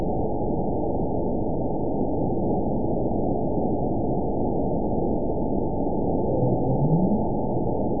event 922702 date 03/14/25 time 21:30:19 GMT (1 month, 2 weeks ago) score 9.60 location TSS-AB04 detected by nrw target species NRW annotations +NRW Spectrogram: Frequency (kHz) vs. Time (s) audio not available .wav